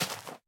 grass2.wav